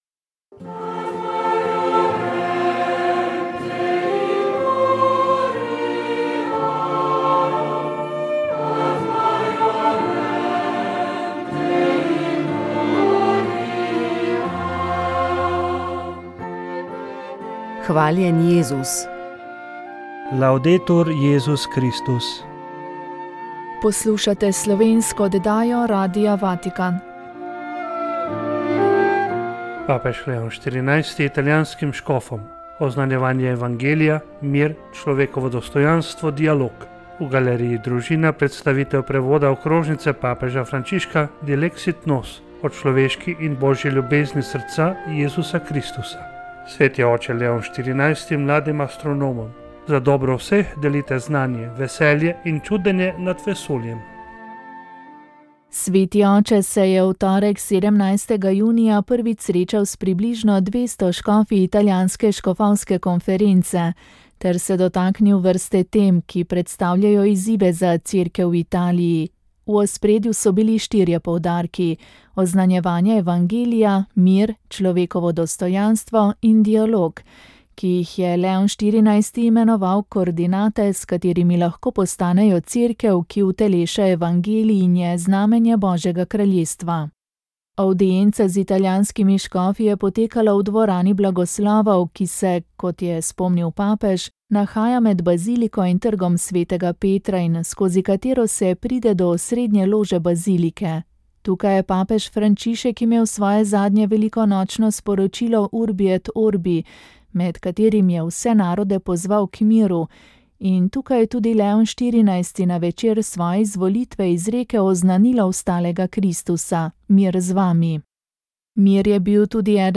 Pred Jamo pod Macesnovo Gorico je minulo soboto potekala spominska slovesnost ob 80. obletnici množičnih izvensodnih pobojev po koncu druge svetovne vojne. Že 35. leto zapored jo je pripravila Nova slovenska zaveza. Slovesnost se je začela s sv. mašo, ki jo je vodil škof Andrej Saje. V oddaji Moja zgodba ste lahko prisluhnili njegovi pridigi in pa kulturnemu programu po sveti maši, ki ga je pripravila Nova slovenska zaveza.